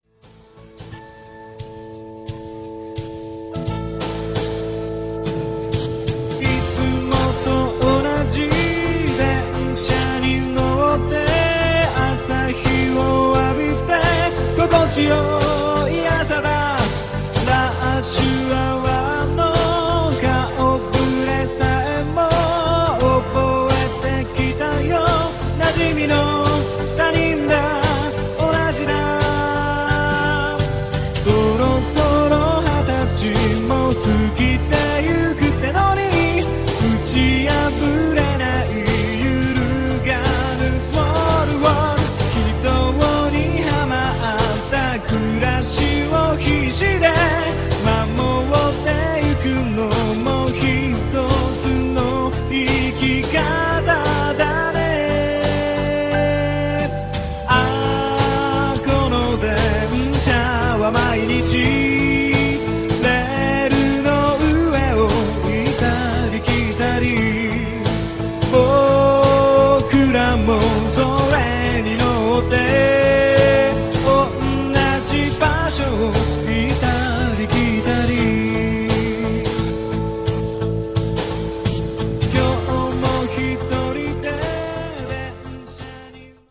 音数を減らし、カスタマイズした結果、何度聴いても飽きない曲になっていると思います。